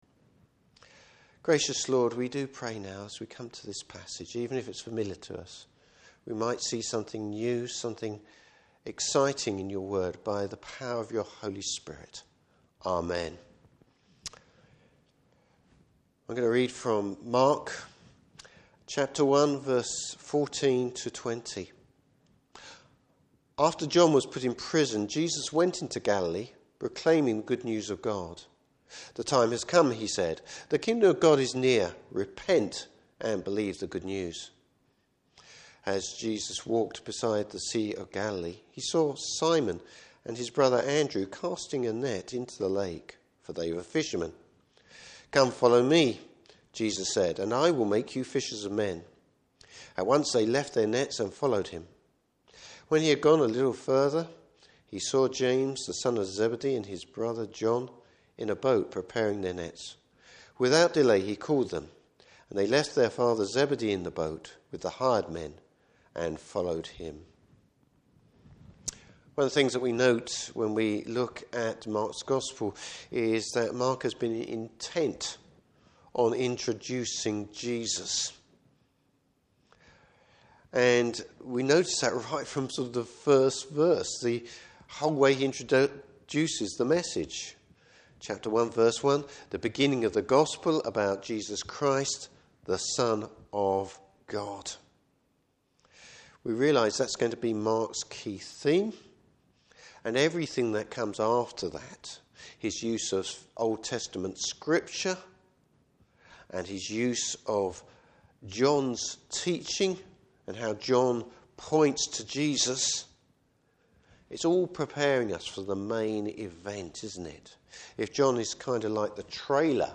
Service Type: Morning Service Jesus’ message and the calling of his first Disciples.